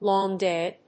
アクセントlóng‐dáy